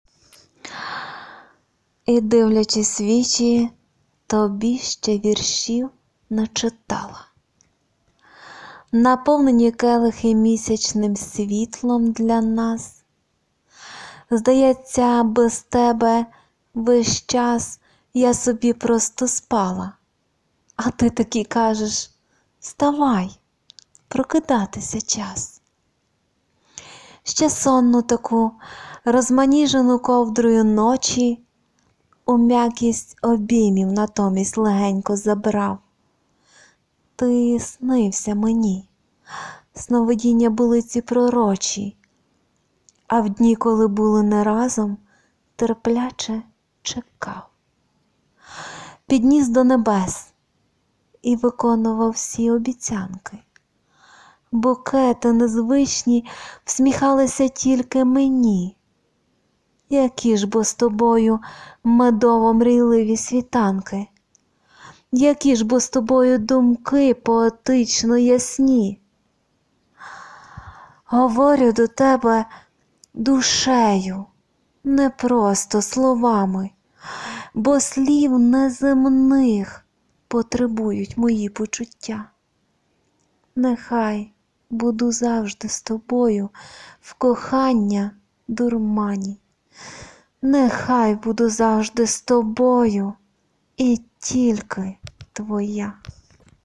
ВИД ТВОРУ: Вірш
А голос у Вас театральний wink
дуже класна поезія! і гарно, що начитали. голос такий юний, ніжно-солодкий)